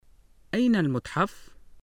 [ʔayna l-mutħaf(-u)]